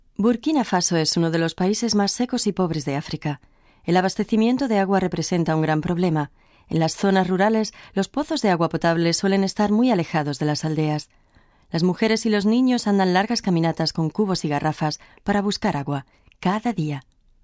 I am a professional Spanish female voice talent with long-time experience.
Sprechprobe: Sonstiges (Muttersprache):
My maternal language is Castilian-Spanish (excellent pronunciation, no regional accent).
My voice is clear and pleasant and can evoke and reflect a wide range of moods and tones: dramatic, ironic, distant, gentle, forceful, direct or suggestive.